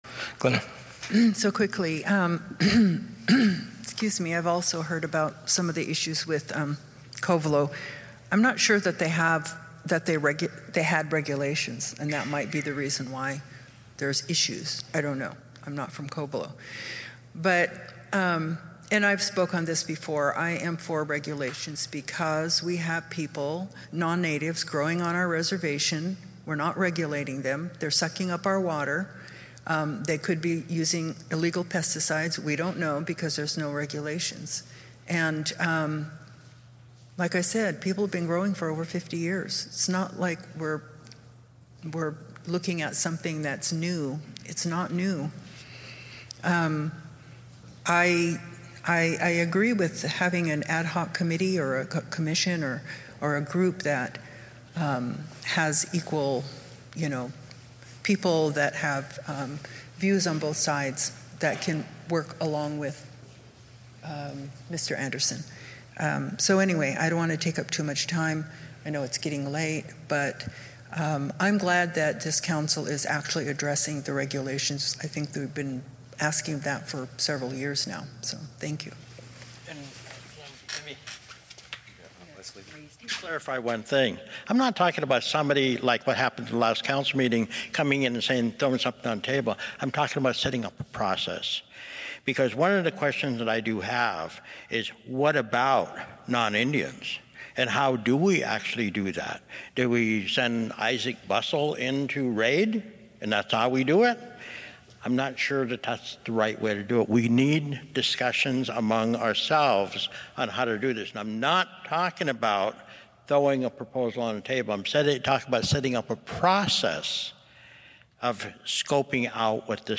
Hoopa Valley Tribe General Meeting Hr. 6.5
This is the last audio segment of the November 16m 2024 Hoopa Valley Tribal General Meeting. This is the continuing input by tribal members on the Cannabis Regulation Presentation and discussion.